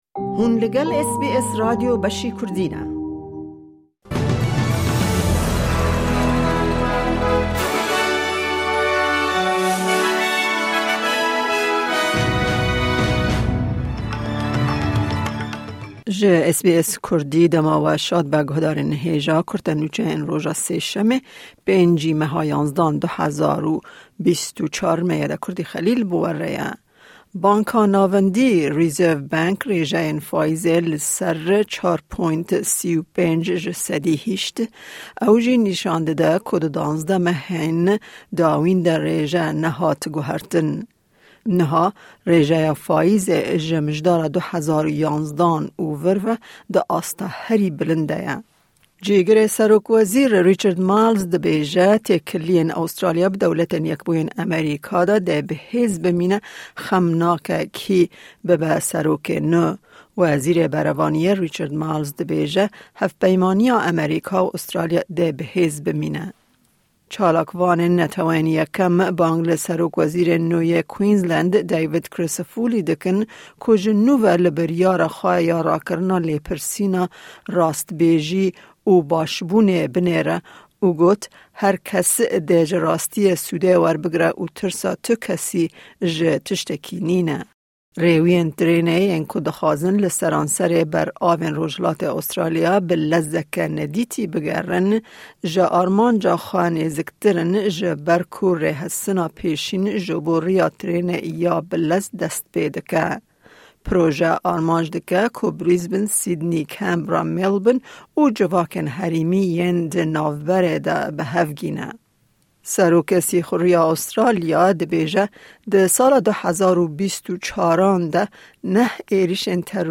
Kurte Nûçeyên roja Sêşemê 5î Mijdara 2024